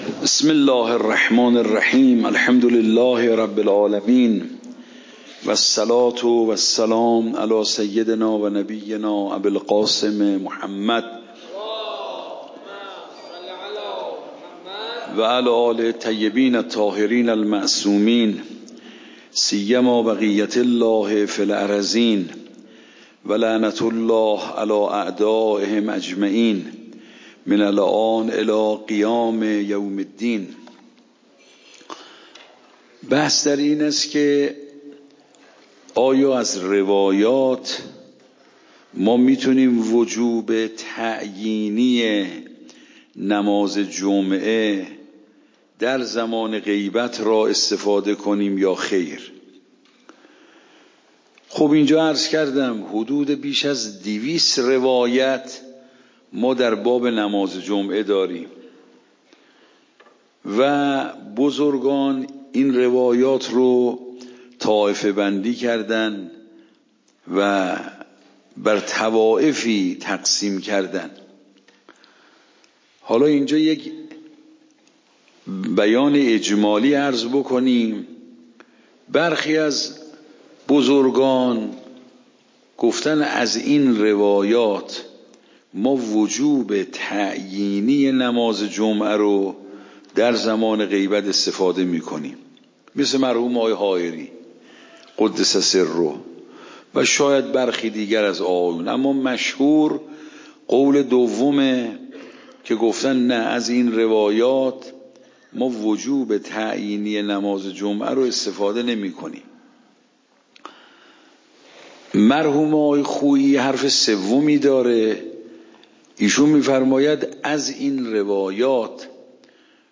موضوع: نماز جمعه فقه خارج فقه
صوت درس